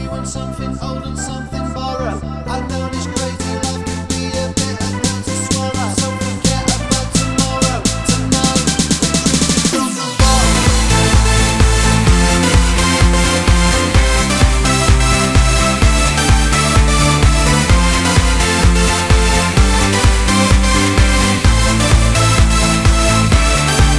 no Backing Vocals R'n'B / Hip Hop 3:56 Buy £1.50